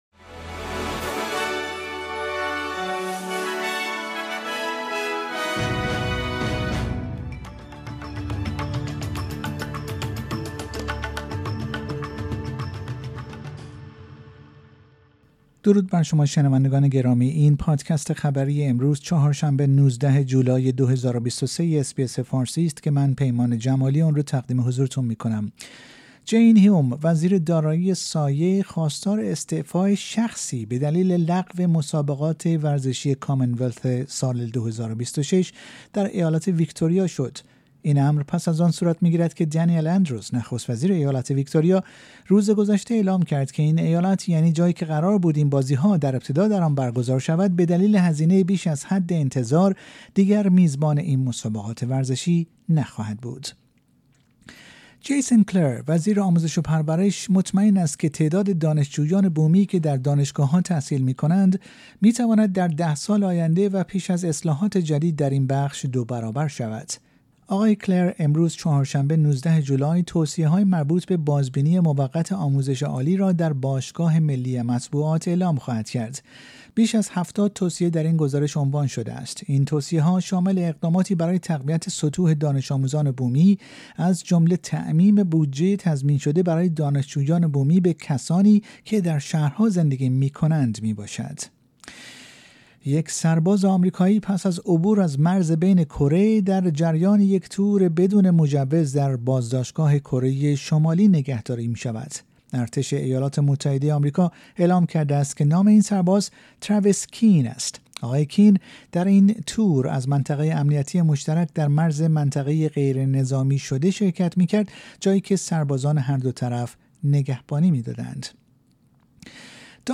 در این پادکست خلاصه‌ای از مهمترین اخبار استرالیا در روز چهارشنبه ۱۹ جولای، ۲۰۲۳ ارائه شده است.